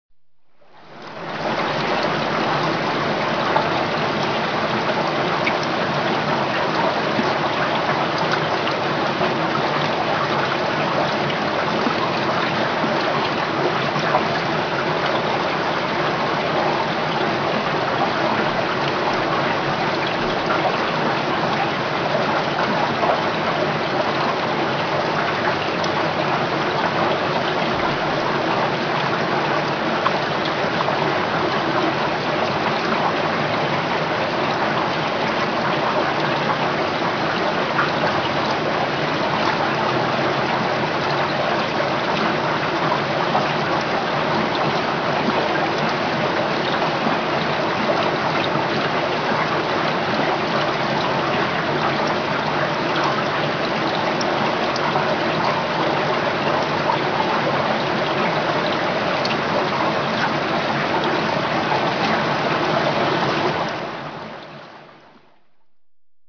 aquarium.ogg